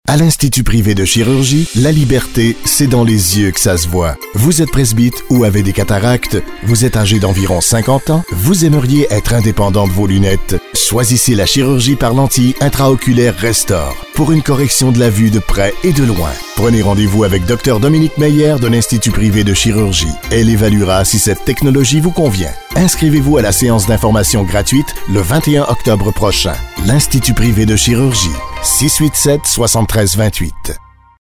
Voix Hors Champ narrateur professionnel producteur radio
Sprechprobe: Werbung (Muttersprache):